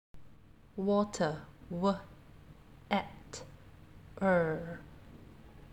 3. Water – ‘w’ ‘at’ ‘er’ (